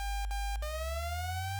defuse.ogg